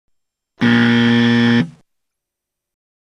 Wrong Buzzer Sound Effect
Category: Sound FX   Right: Personal